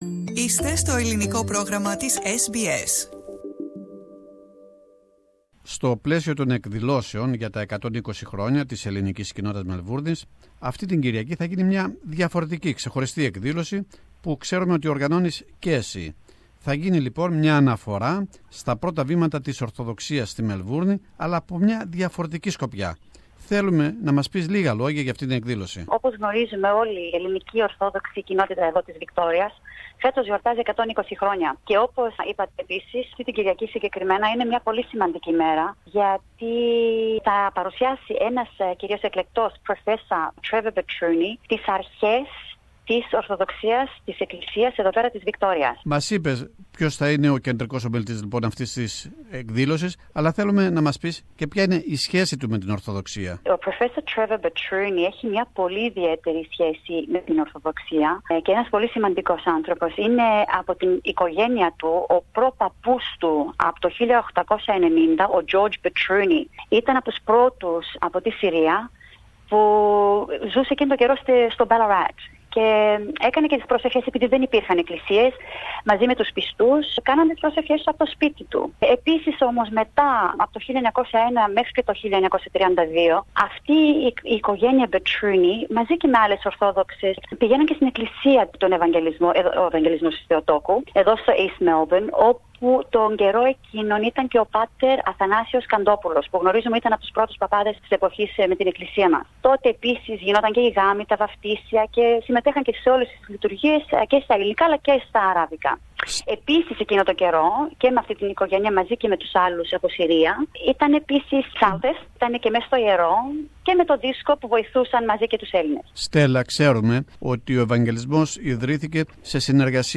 Lecture about the the beginnings of the Orthodox Church in Victoria Source: Supplied